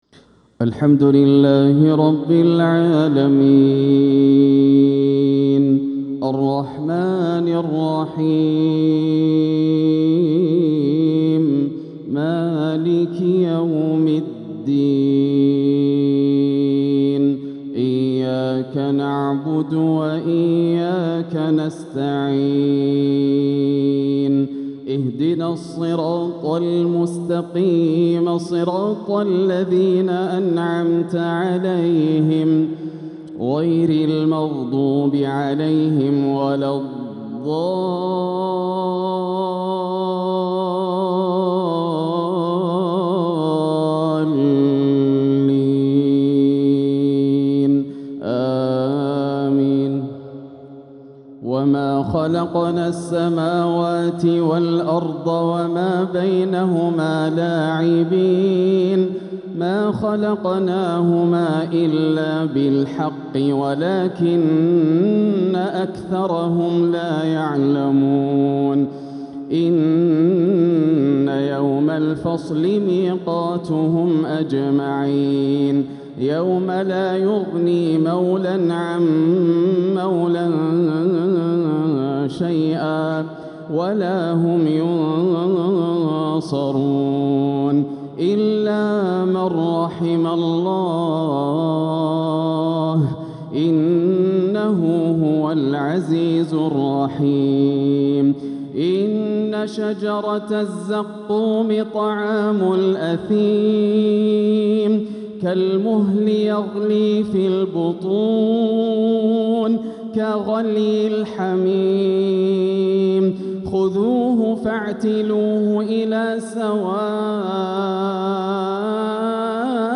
خواتيم سورة الدخان بآداء مؤثر | مغرب الخميس 6-6-1447هـ > عام 1447 > الفروض - تلاوات ياسر الدوسري